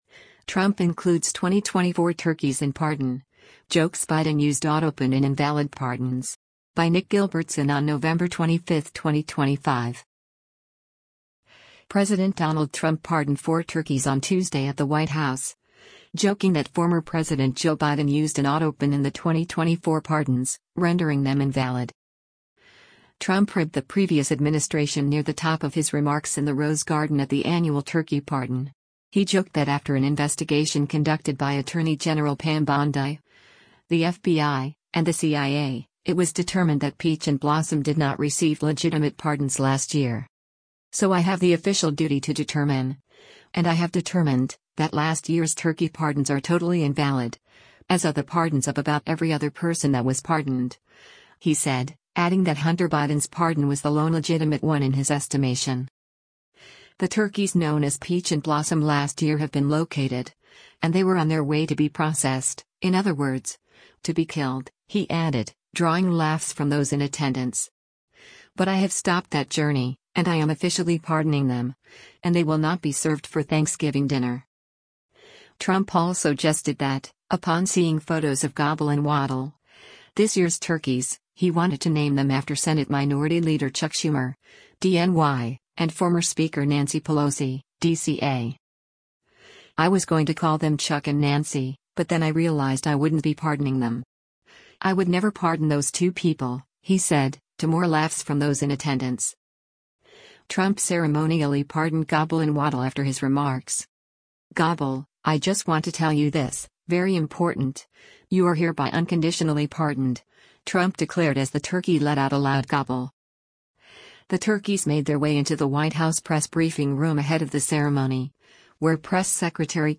Trump ribbed the previous administration near the top of his remarks in the Rose Garden at the annual turkey pardon.
“Gobble, I just want to tell you this, very important, you are hereby unconditionally pardoned!” Trump declared as the turkey let out a loud gobble.